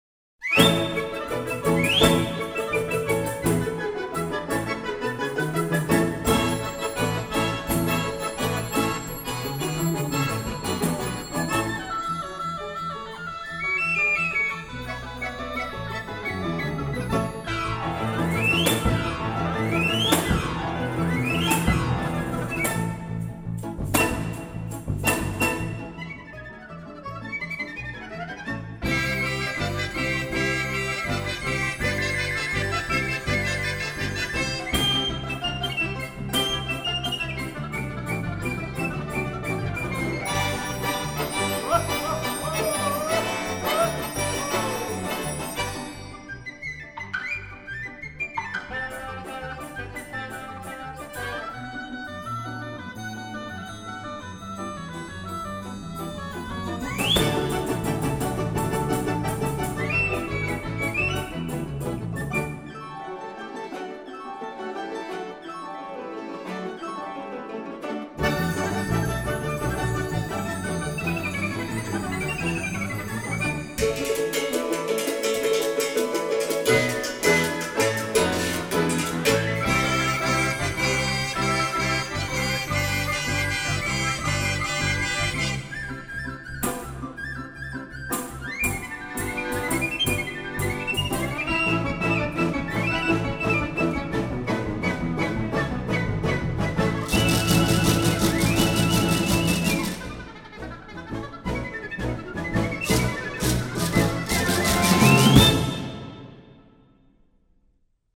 Russian Folk Instruments Soloist's Band